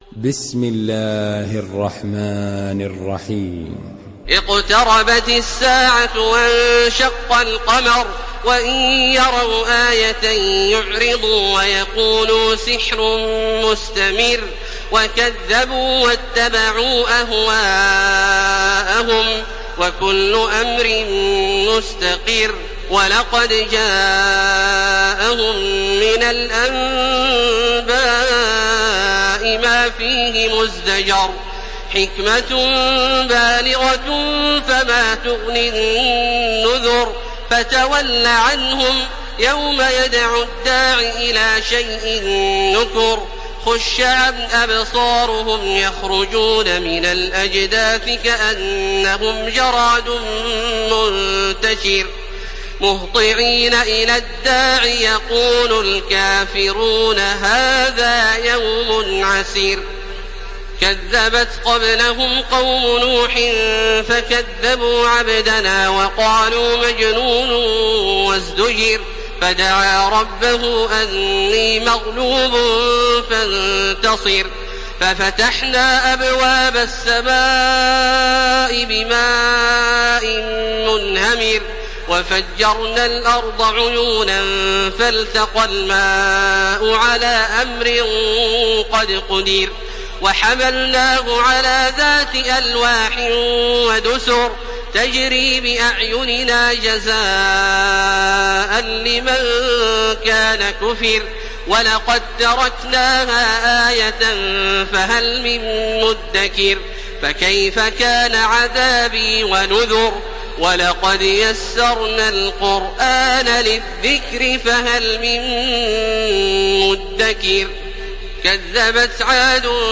سورة القمر MP3 بصوت تراويح الحرم المكي 1431 برواية حفص
مرتل